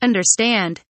understand kelimesinin anlamı, resimli anlatımı ve sesli okunuşu